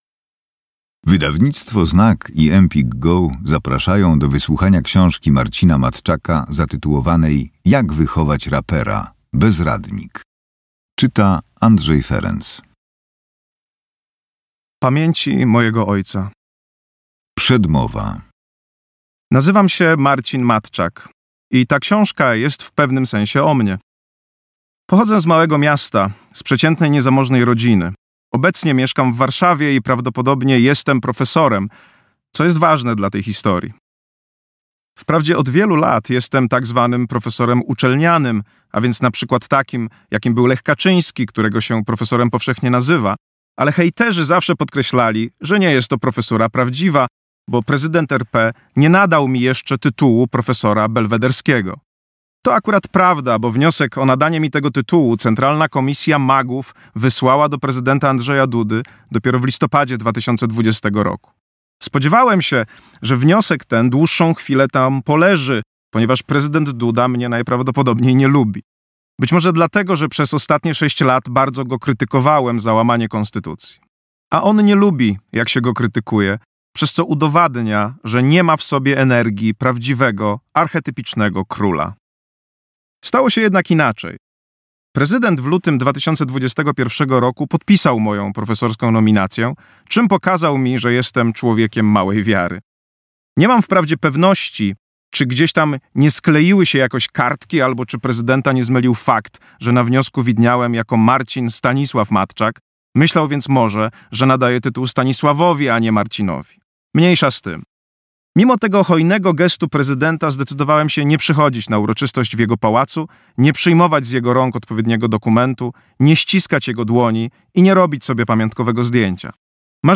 Jak wychować rapera. Bezradnik - Matczak Marcin - audiobook